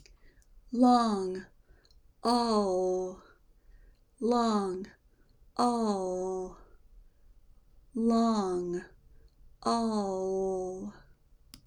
The Dark L Sound
Can you hear it as I say the words long (light l) and all (dark l)?
Long (light l) and All (dark l)